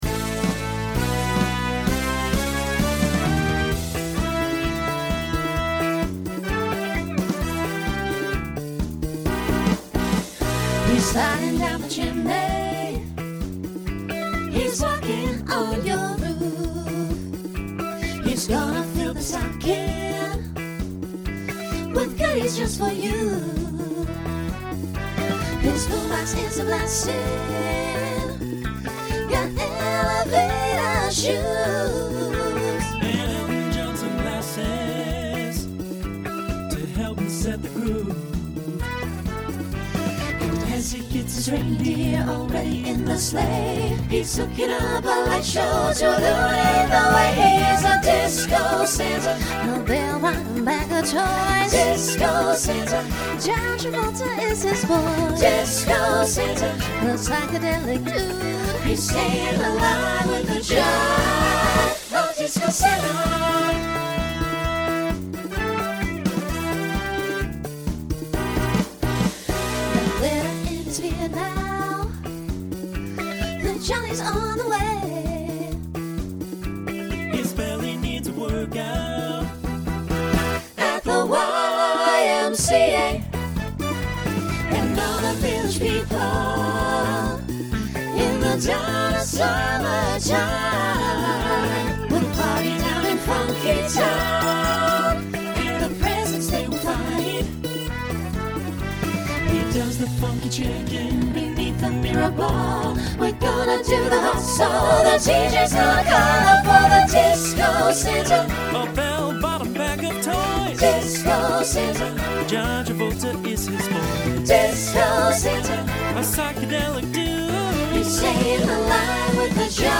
Genre Disco , Holiday
Novelty Voicing SATB